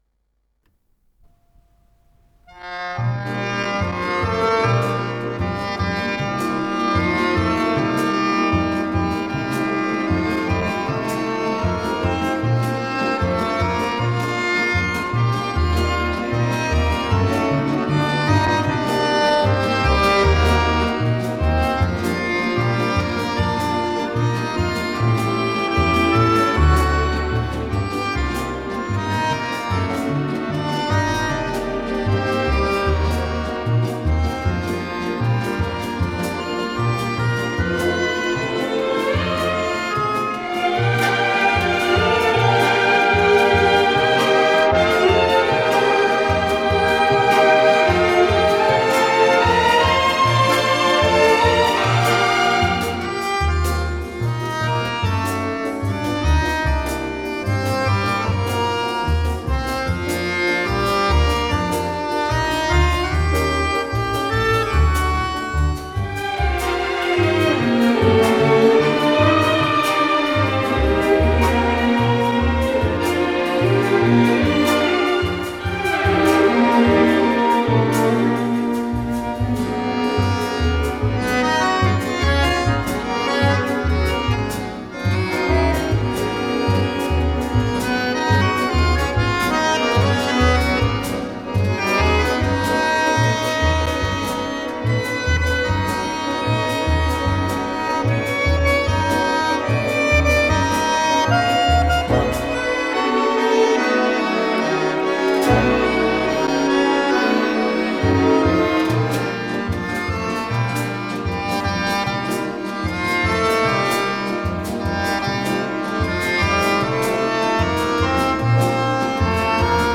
с профессиональной магнитной ленты
ПодзаголовокРе мажор
Скорость ленты38 см/с